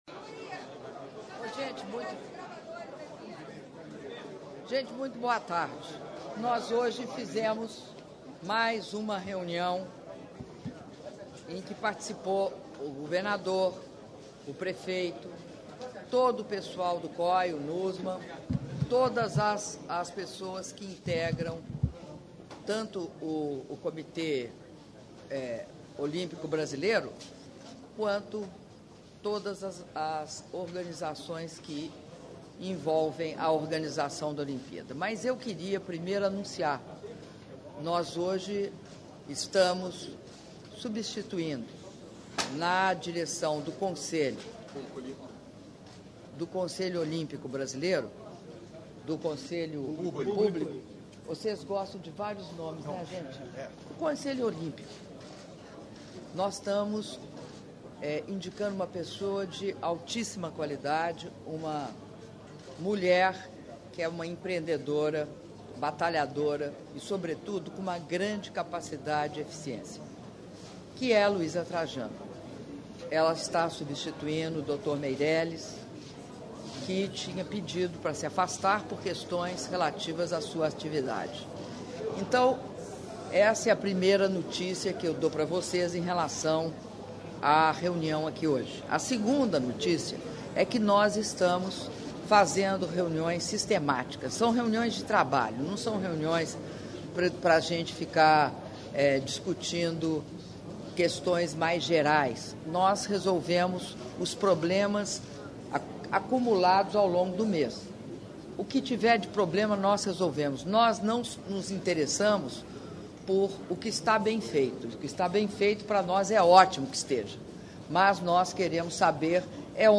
Áudio da entrevista coletiva concedida pela Presidenta da República, Dilma Rousseff, após reunião de atualização do Projeto dos Jogos Rio 2016 - Rio de Janeiro/RJ (05min35s)